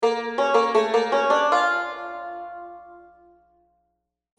Ambient sound effects